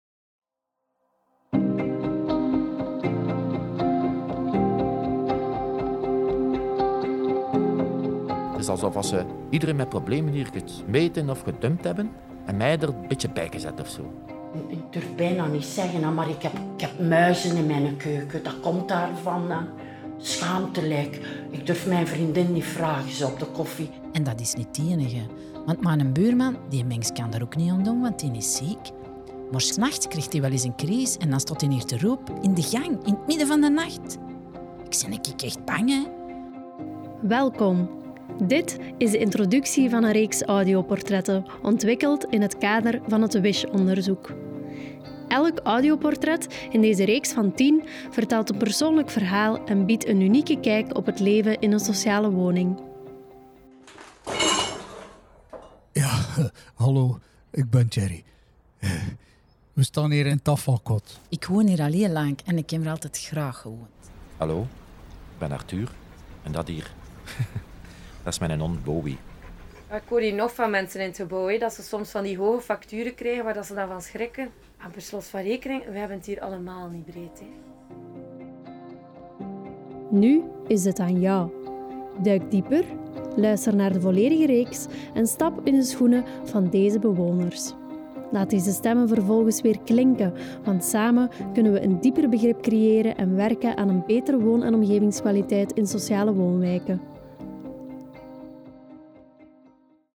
Teaser WiSH-audioportretten.mp3